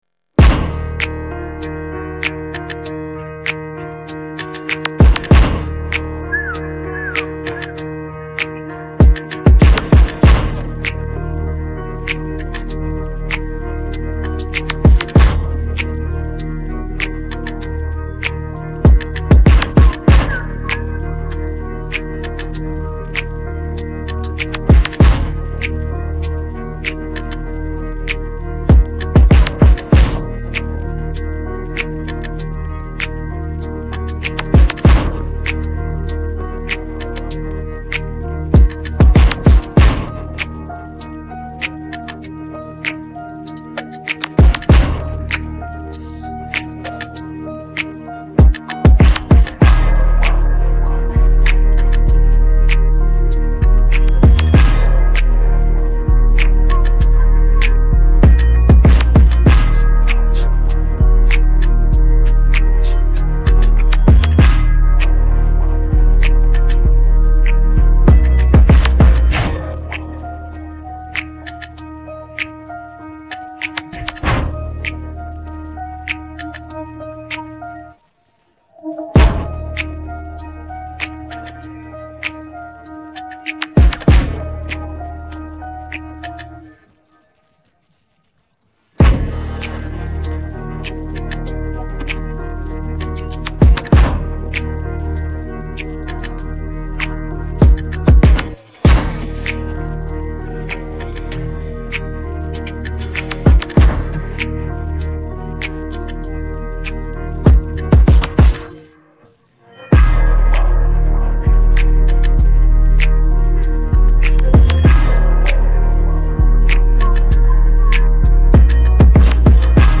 Hintergrundmusik